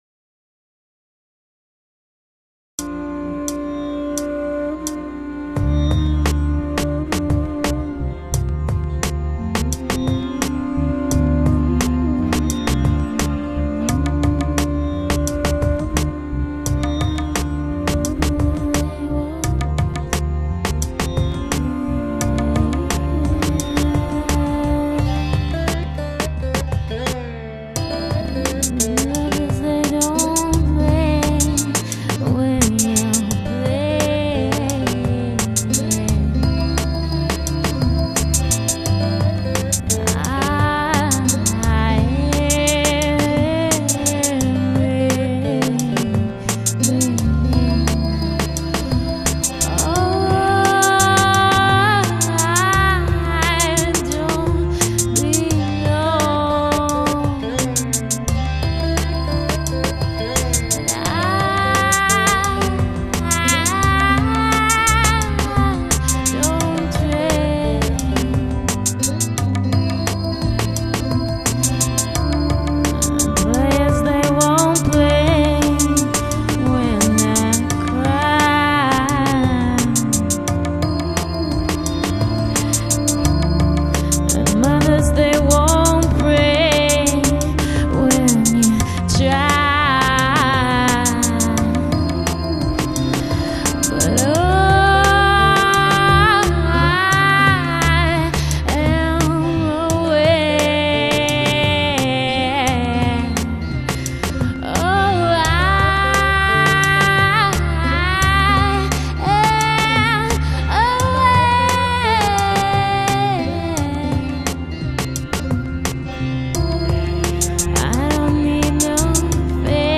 dance/electronic
ambient/electronic
World music